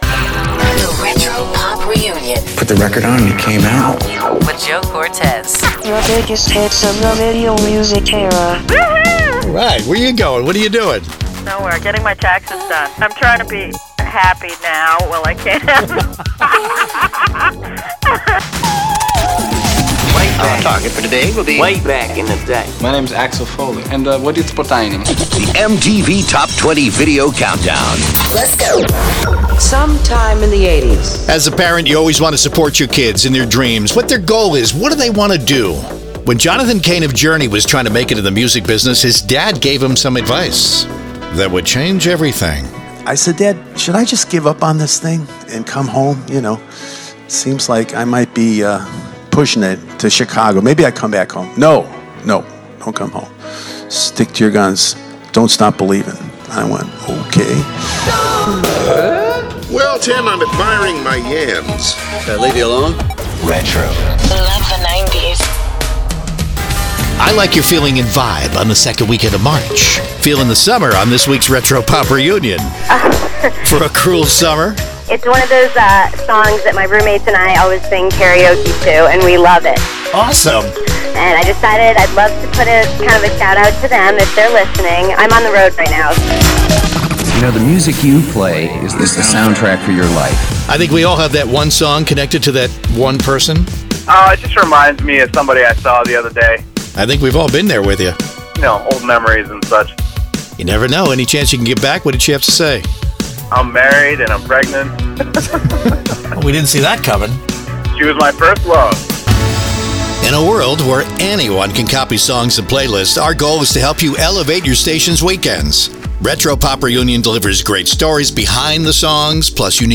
The biggest hits of the VIDEO MUSIC ERA!
CLASSIC HITS, ADULT HITS & AC